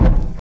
Seven_Kick.wav